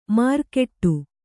♪ mārkeṭṭu